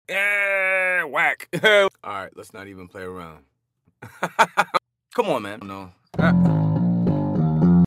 Funny Sound For Videos 😆 sound effects free download